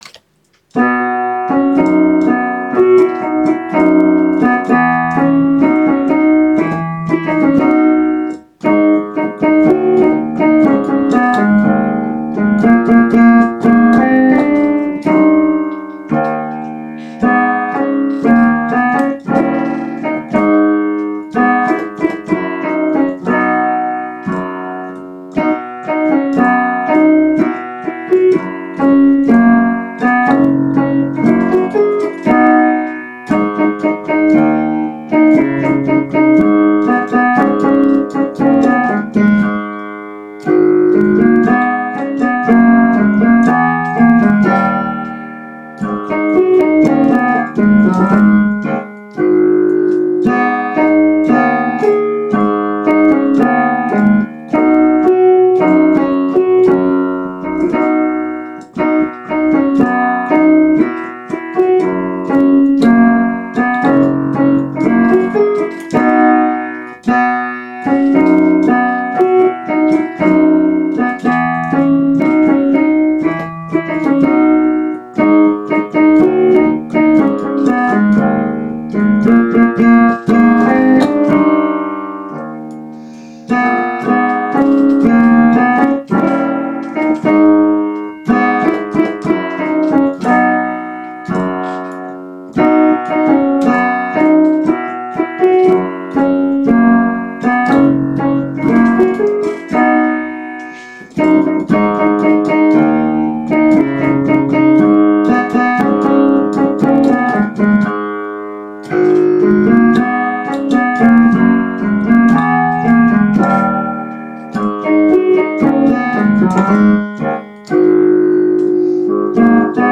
Planks A' Crashing is in the key of B major, consisting of sixteen 4-bar verses. Its melodic structure has eight verses, repeated twice, with choruses every four bars.